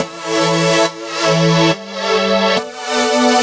Index of /musicradar/french-house-chillout-samples/140bpm/Instruments
FHC_Pad B_140-E.wav